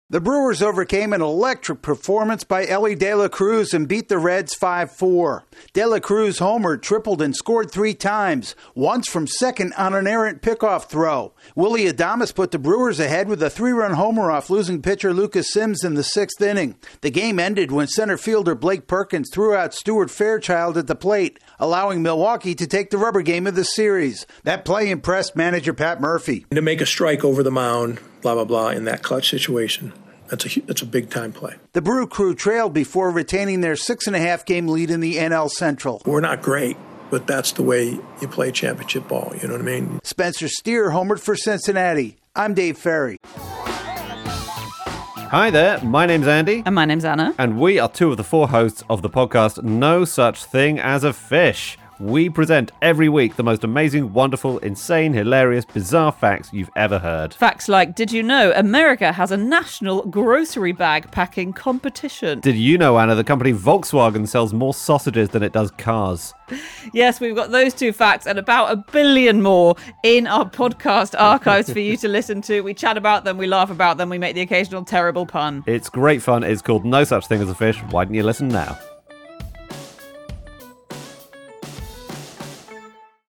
I big blast and a great throw allow the Brewers to withstand a strong performance by a Reds budding star. AP correspondent